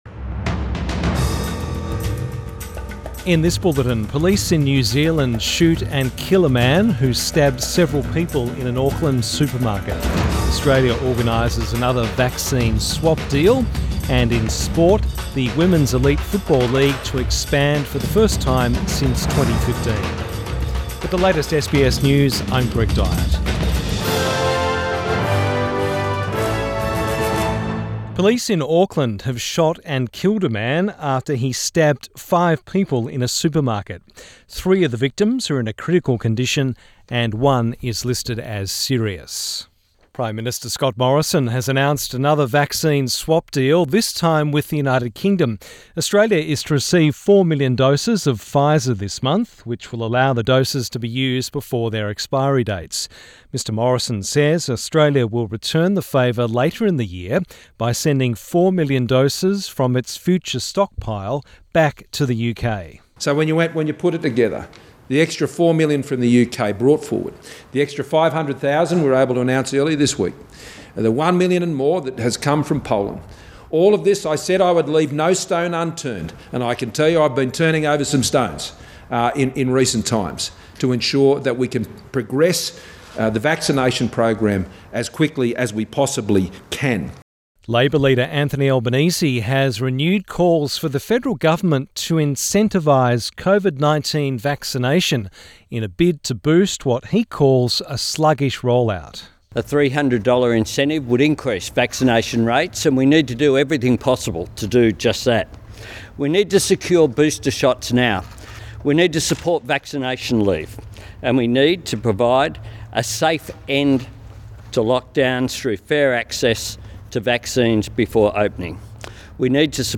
PM bulletin 3 September 2021